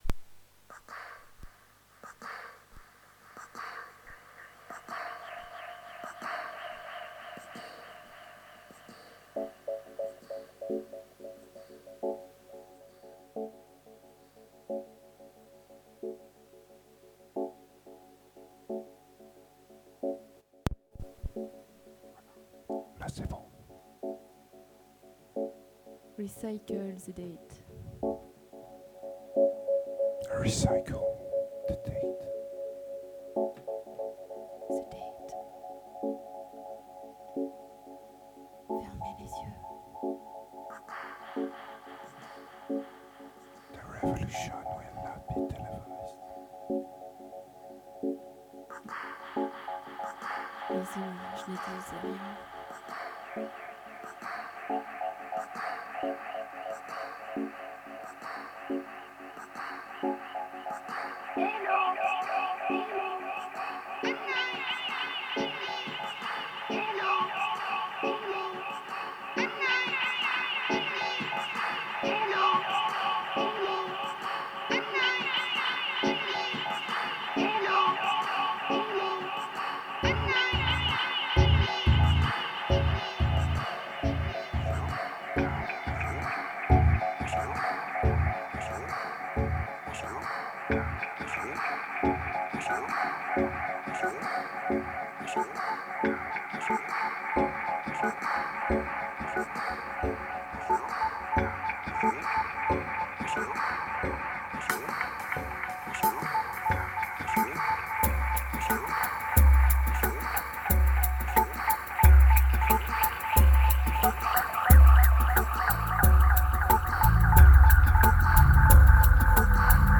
2617📈 - 83%🤔 - 90BPM🔊 - 2012-09-30📅 - 208🌟